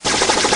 飞行器Flying Machine是建筑工人的新发明，音效为飞行声。
飞行音效
CR_flying_machine_loop_01.mp3